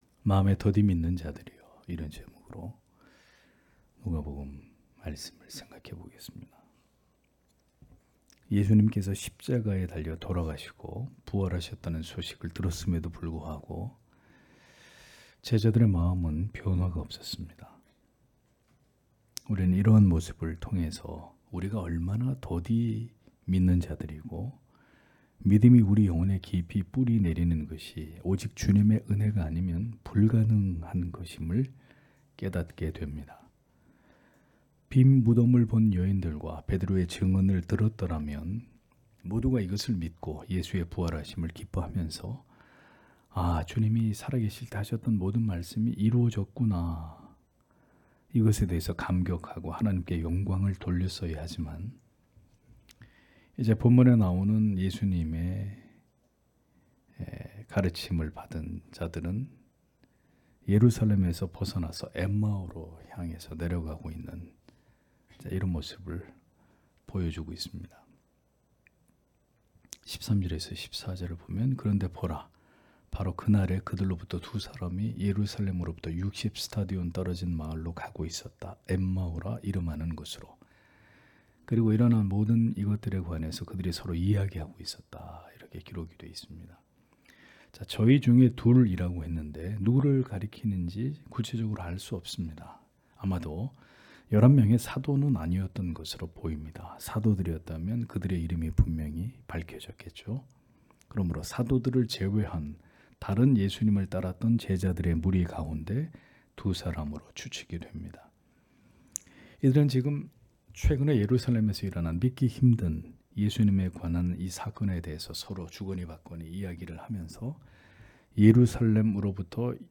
금요기도회 - [누가복음 강해 184] '마음에 더디 믿는 자들이여' (눅 24장 13- 27절)